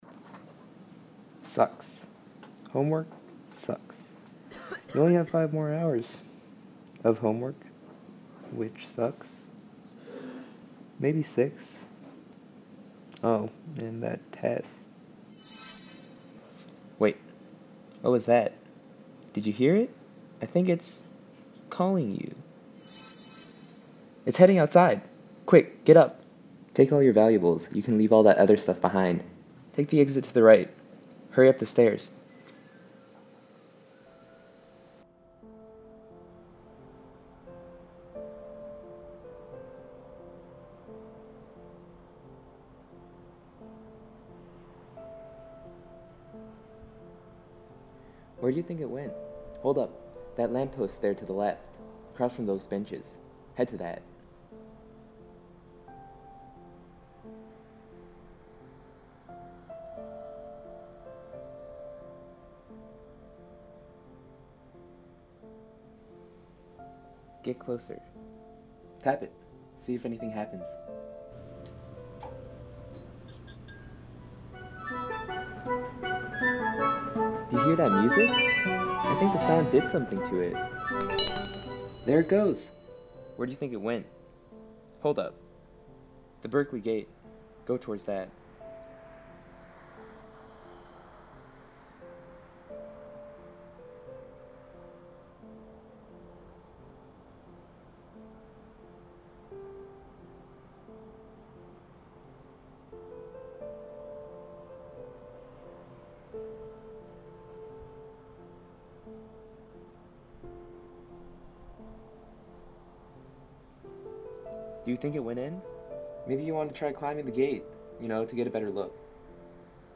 Audio Walk